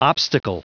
Prononciation du mot obstacle en anglais (fichier audio)
Prononciation du mot : obstacle